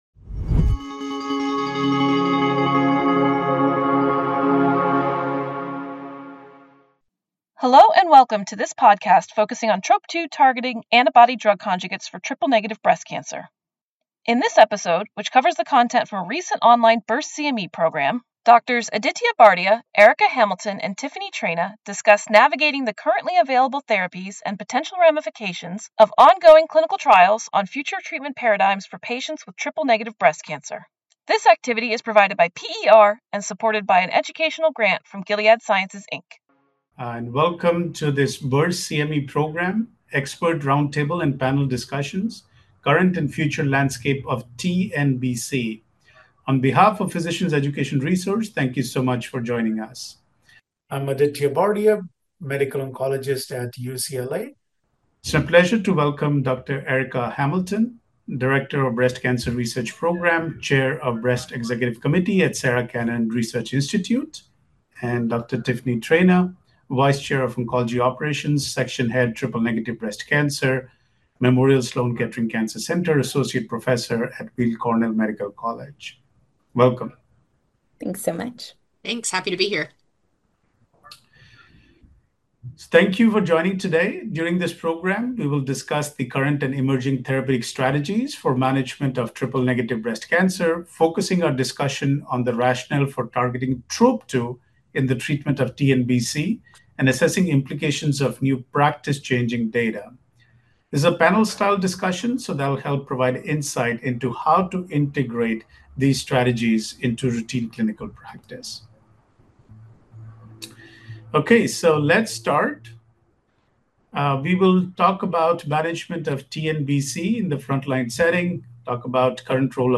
Expert Roundtable and Panel Discussions: Current and Future Landscape of TNBC